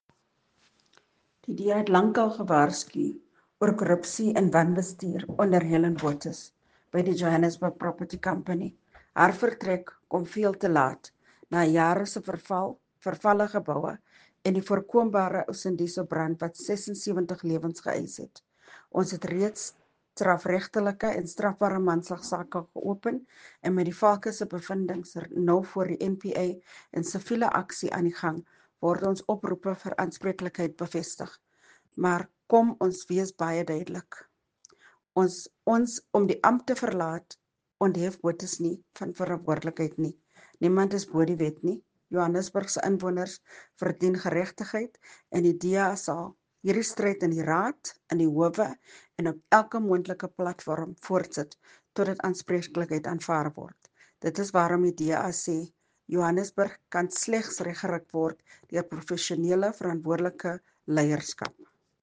Note to Editors: Please find English and Afrikaans soundbites by Cllr Belinda Kayser-Echeozonjoku